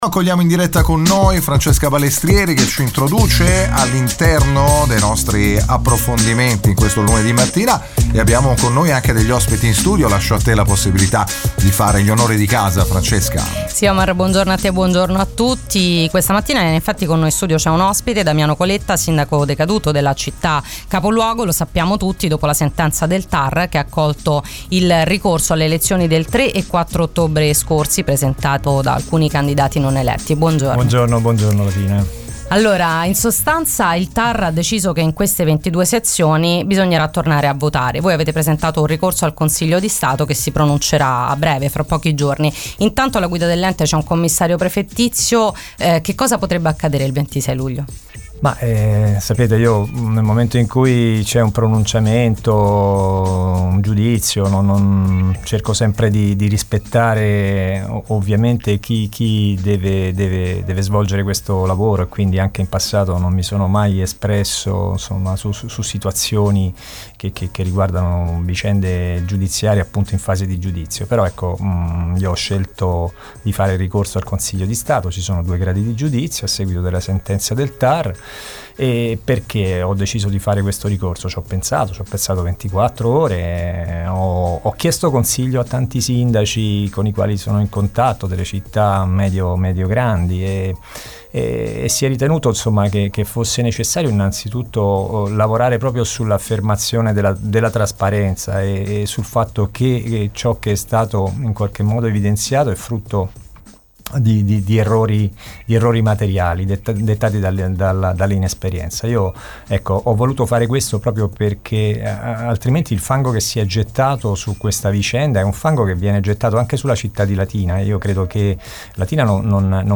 Questa mattina su Radio Immagine il sindaco decaduto Damiano Coletta ha fatto il punto della situazione e anche sui fondi e progetti che sono in fase di completamento.